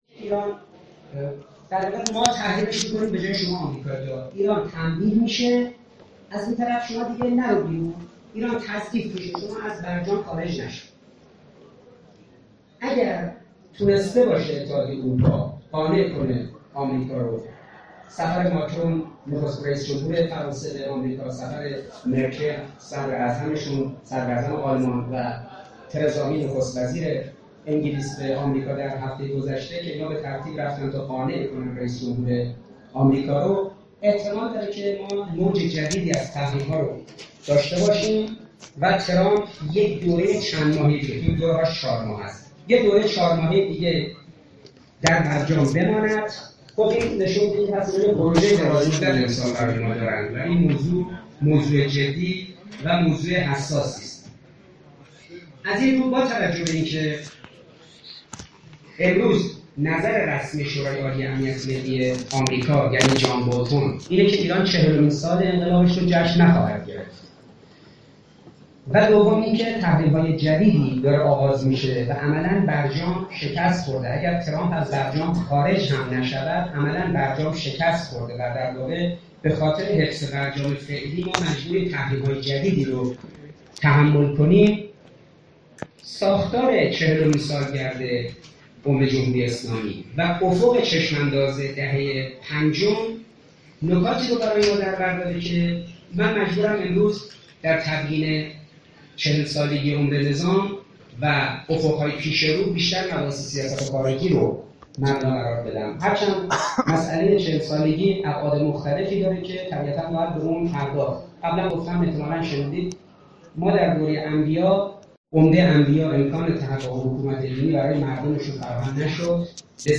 ۱۸ اردیبهشت ۹۷ – اراک، دانشگاه آزاد اسلامی
9 می 2018 سخنرانی‌های دانشگاهی, سخنرانی ها 7 دیدگاه‌ها